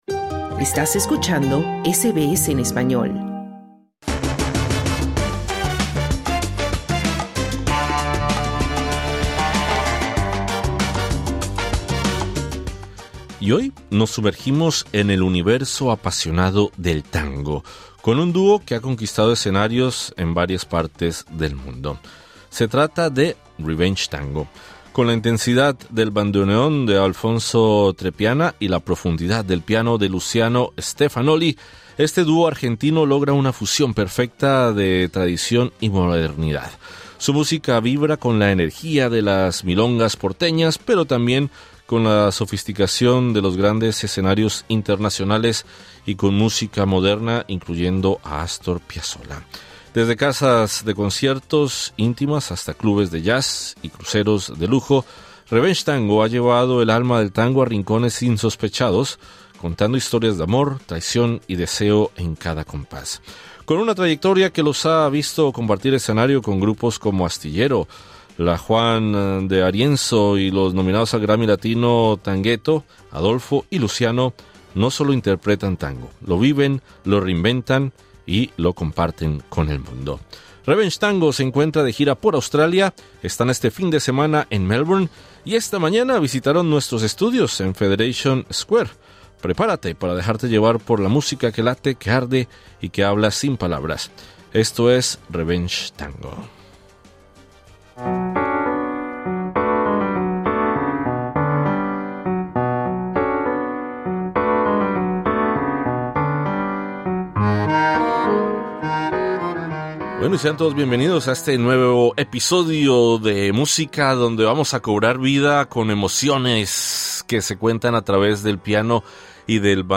Desde Buenos Aires hasta Australia, el dúo argentino Revenge Tango reinventa el tango con una fusión poderosa de bandoneón y piano. Conversamos